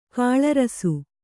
♪ kāḷarasu